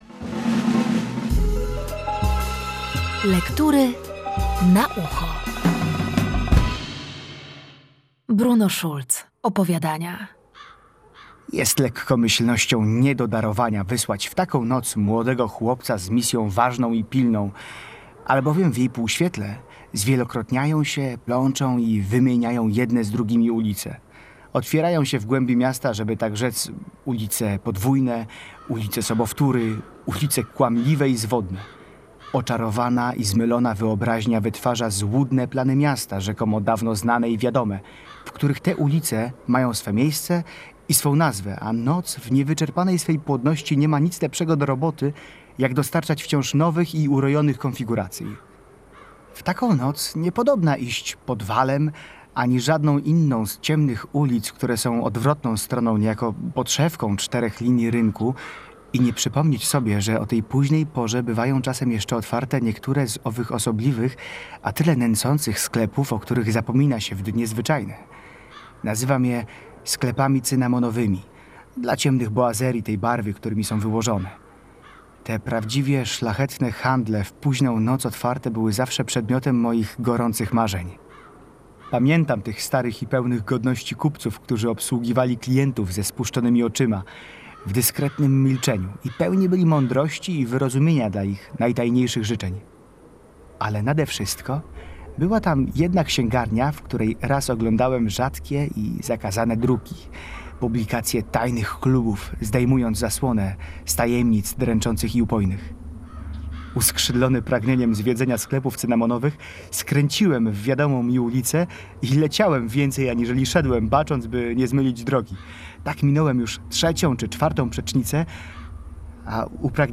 Od 25 maja do 5 czerwca w każdy dzień powszedni o 13:35 i 20:35 na antenie Radia Gdańsk prezentować będziemy fragmenty lektur szkolnych, wraz z komentarzem.